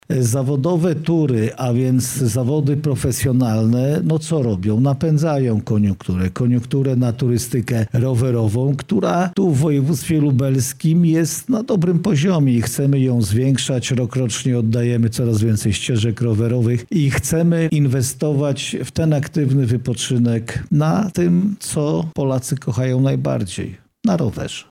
Marszałek Jarosław Stawiarski zaznaczył, że całe wydarzenie ma duże znaczenie dla rozwoju naszego województwa: